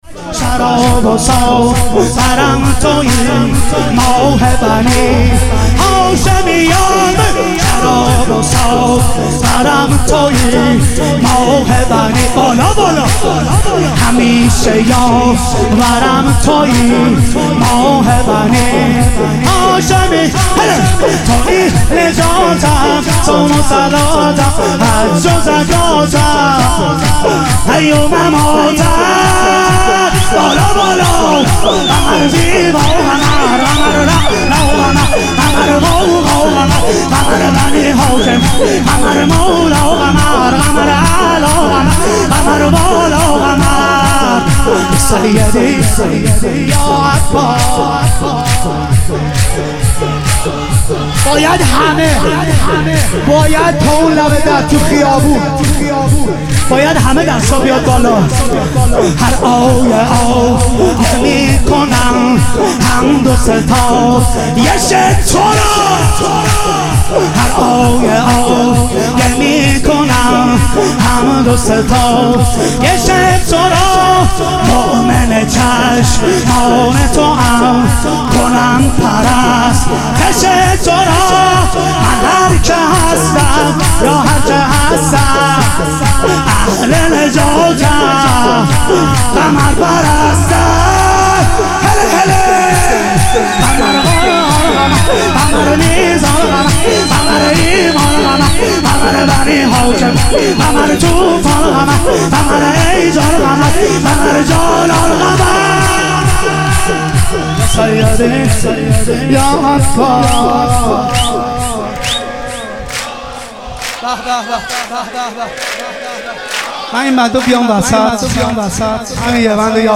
ظهور وجود مقدس حضرت عباس علیه السلام - تک